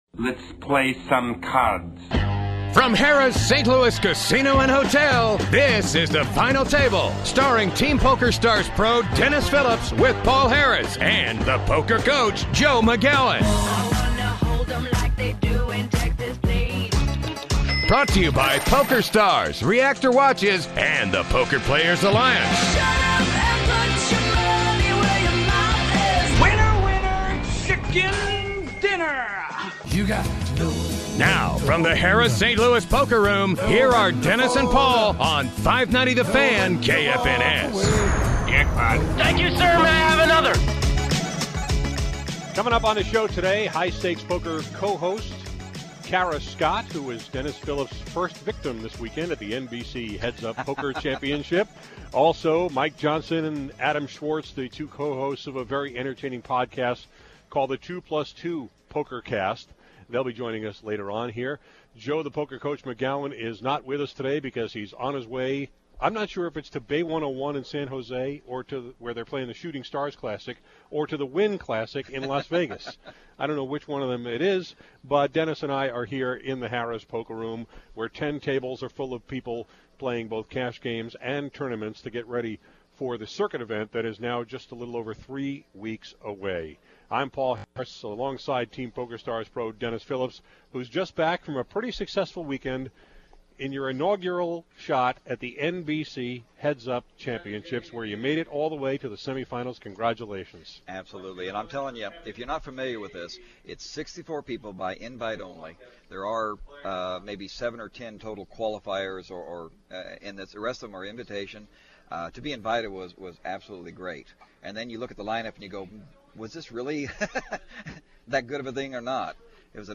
This week on my poker radio show, The Final Table, Dennis Phillips and I talk about his weekend at the NBC National Heads-Up Championship in Las Vegas, where he competed against Chris Ferguson, Doyle Brunson, and Eli Elezra — all of whom Dennis beat before losing in a semi-final match to Annie Duke.